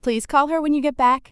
「コーラー」と聞こえたんじゃないかなと思います。
「call her」の「h」は聞こえず「caller」「コーラー」